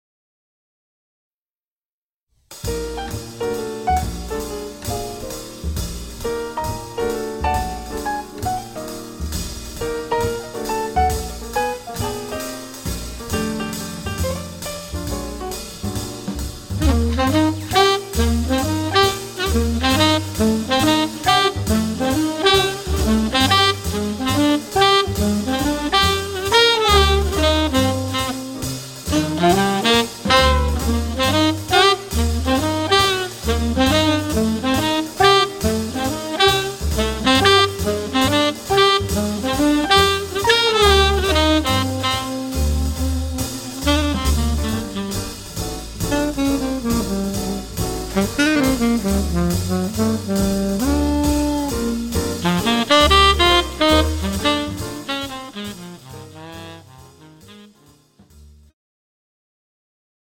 The Best In British Jazz
Recorded at Red Gable Studio, West London 31st March 2009